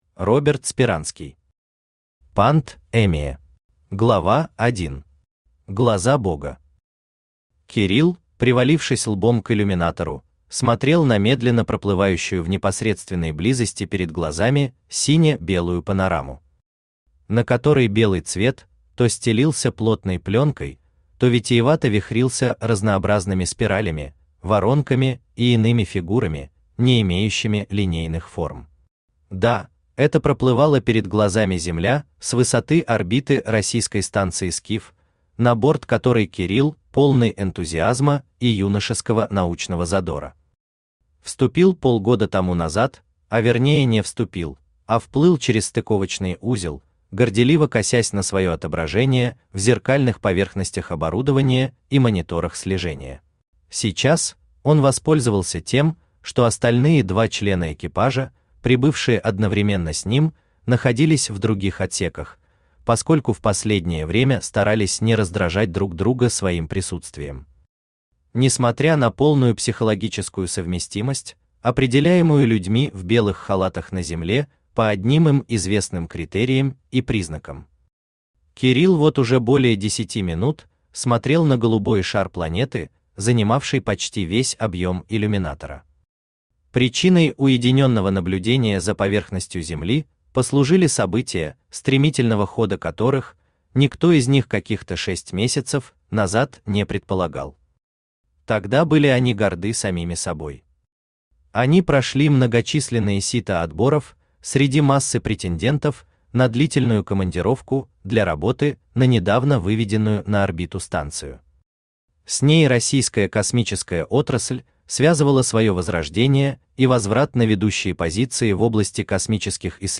Aудиокнига ПандЭмия Автор Роберт Юрьевич Сперанский Читает аудиокнигу Авточтец ЛитРес.